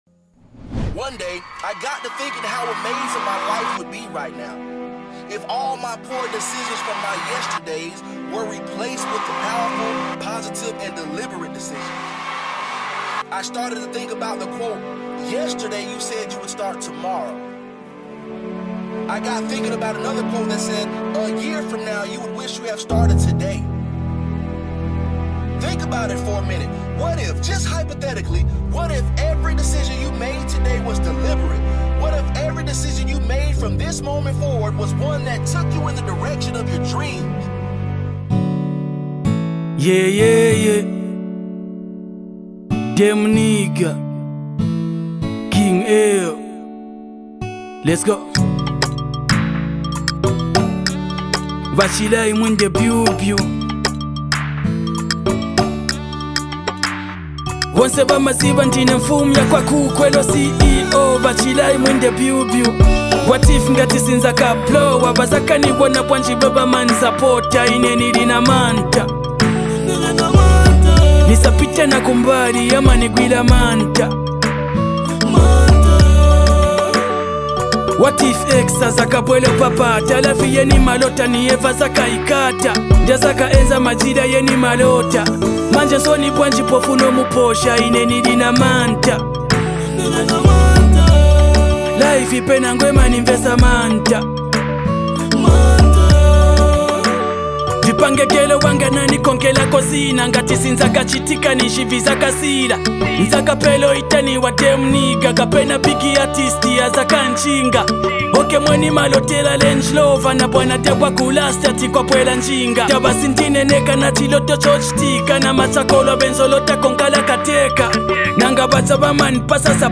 A Powerful Afro-Fusion Hit